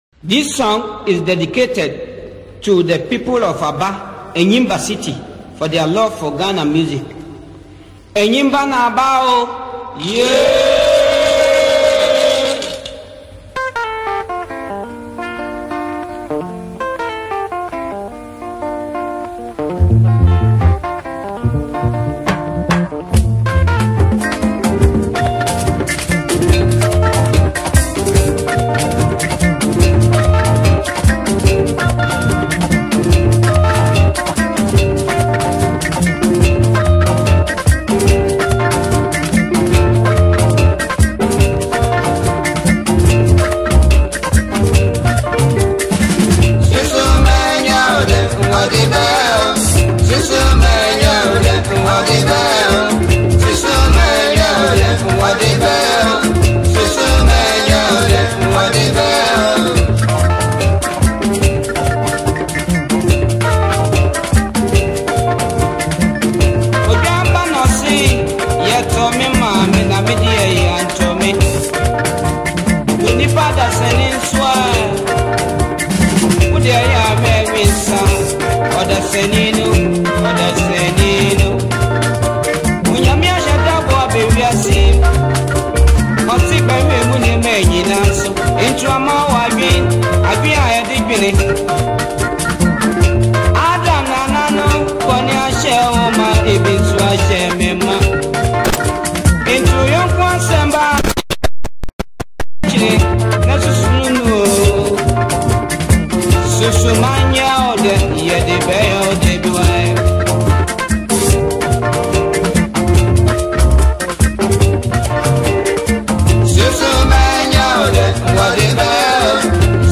old highlife song